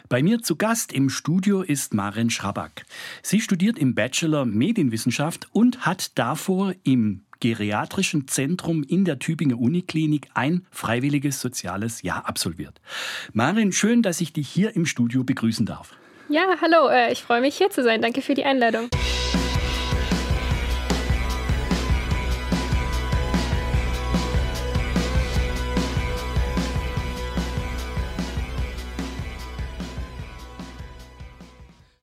Sendung_398_Ton-Teaser.mp3